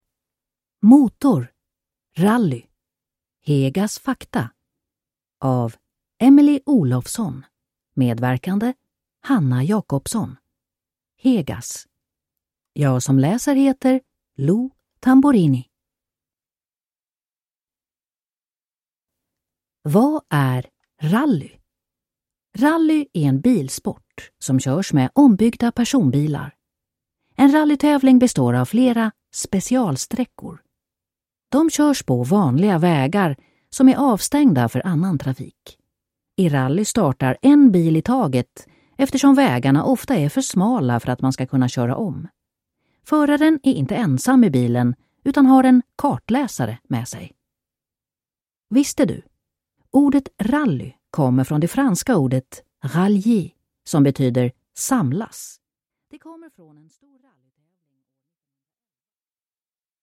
Rally (ljudbok) av Emelie Olofsson